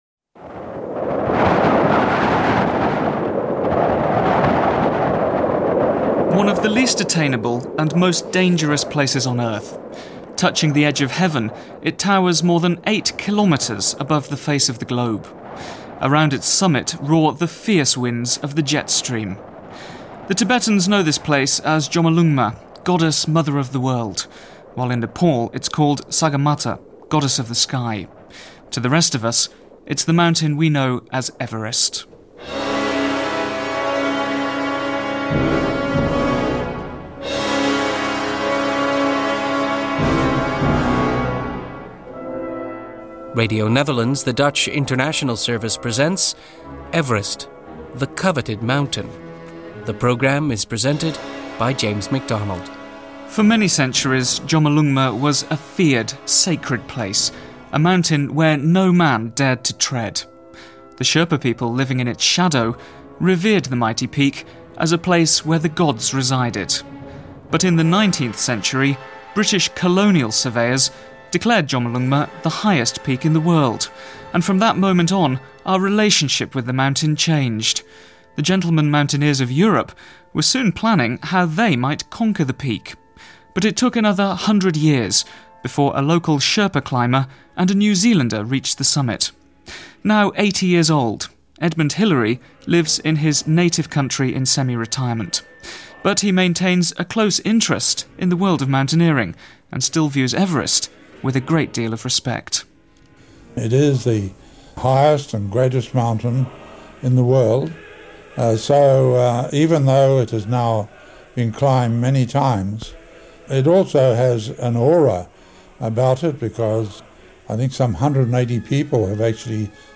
Crampons and spikes feature heavily in this heady documentary